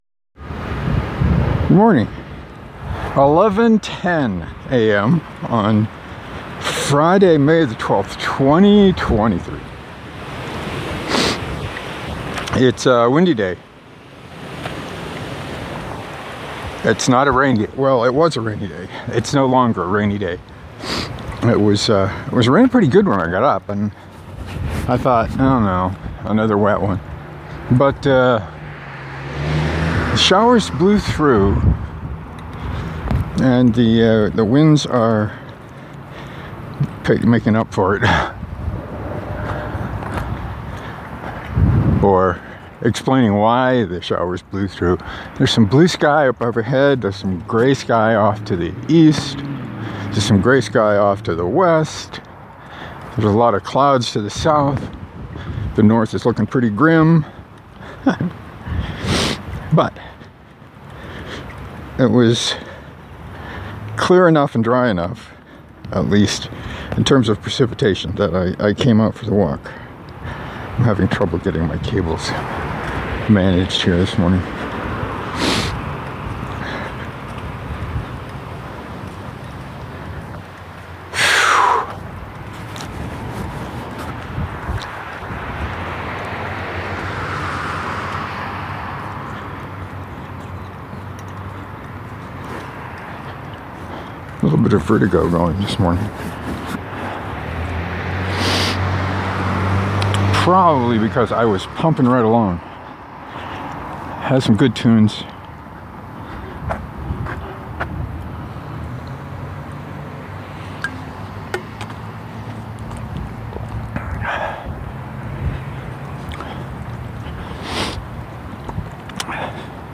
I talked too much about Kickstarter and paperbacks, then ran into the winds again for the last 3 minutes.